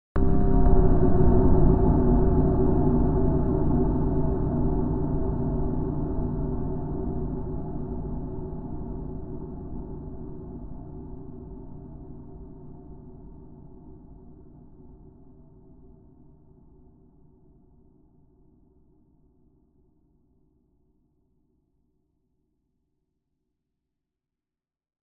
zapsplat_sound_design_hit_long_dark_decay_groaning_23732
Tags: ghost